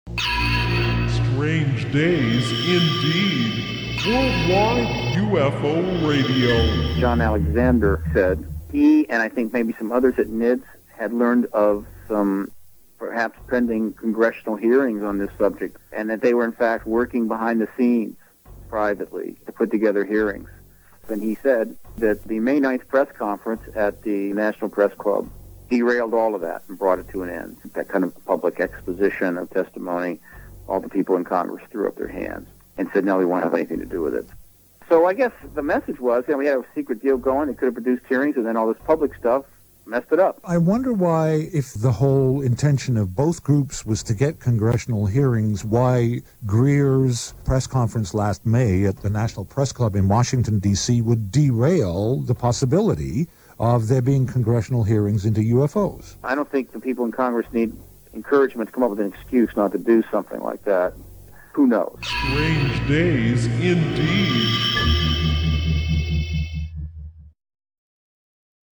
Live from the Laughlin, Nevada UFO Congress